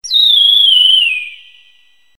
One of Yoshi's voice clips in Mario Party 2